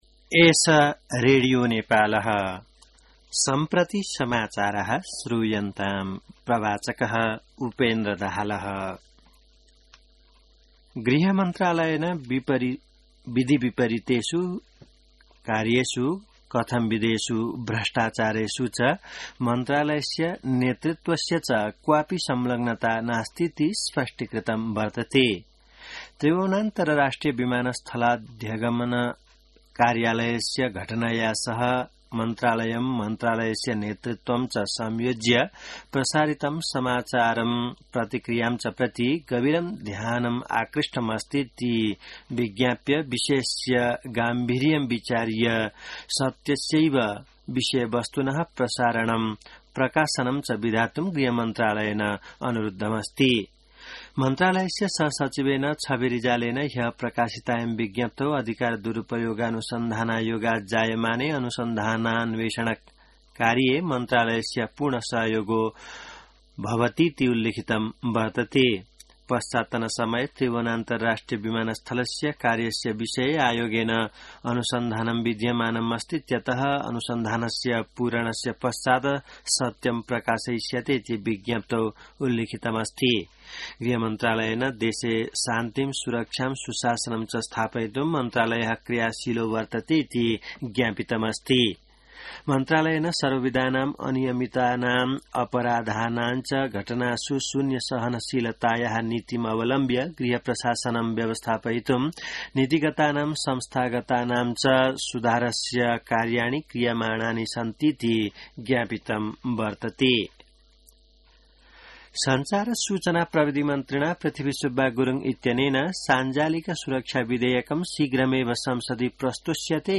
An online outlet of Nepal's national radio broadcaster
संस्कृत समाचार : ११ जेठ , २०८२